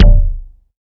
Kicks
KICK.12.NEPT.wav